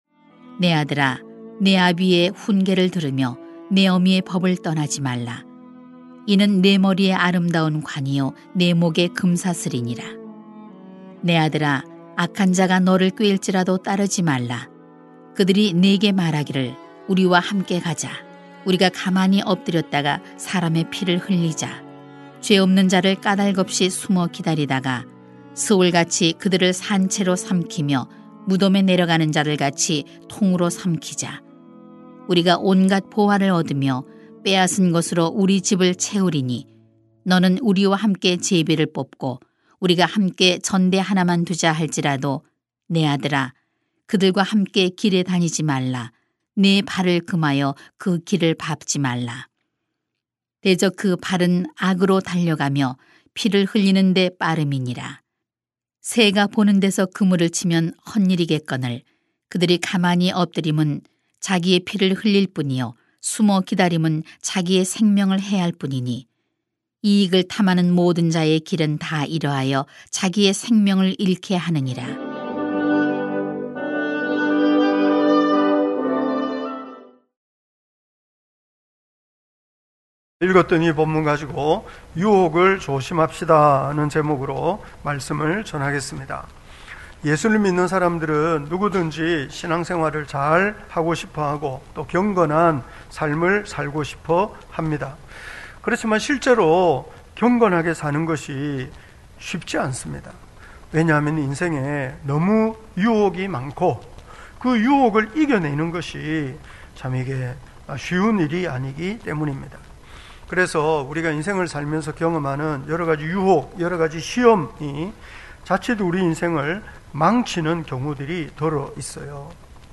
2021.5.2 유혹을 조심합시다 > 주일 예배 | 전주제자교회